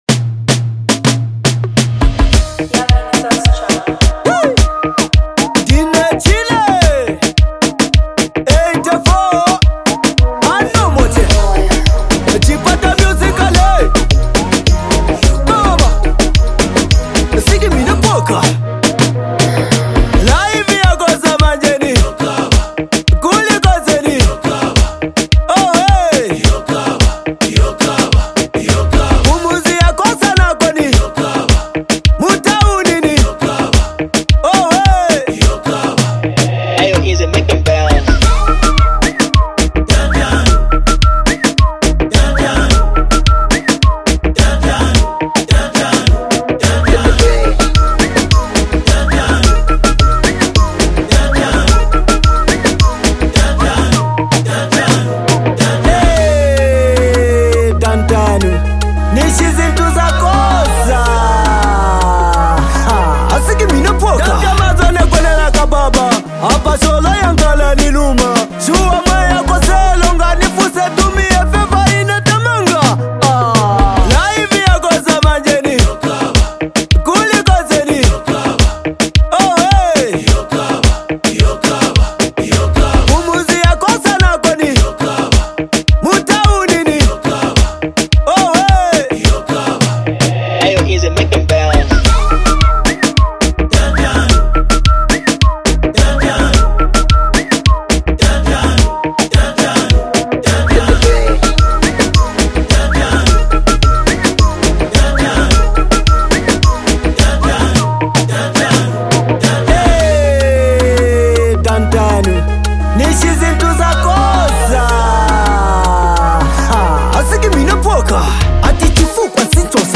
blending raw street-inspired vocals
and rhythmic beats that reflect life’s challenges